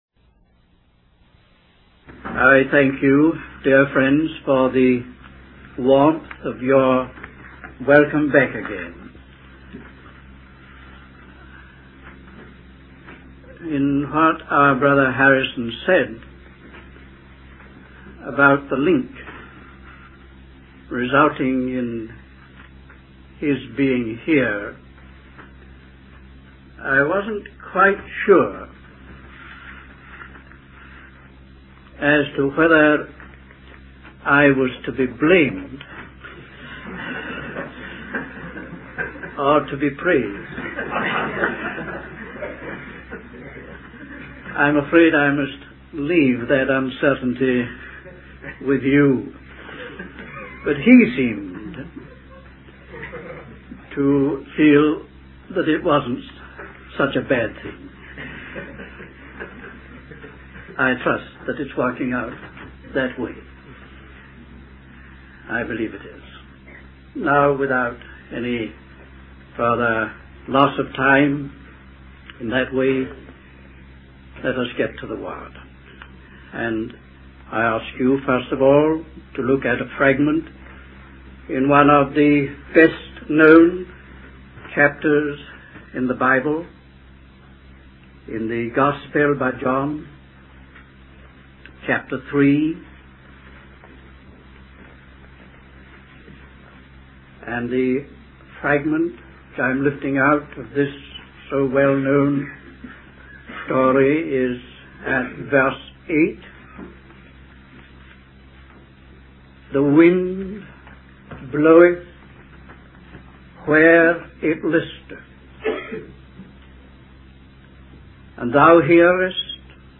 Los Angeles Conference #1